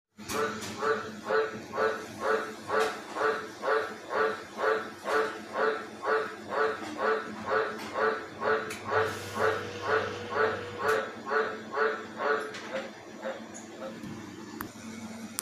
See? Honk honk honk honk ...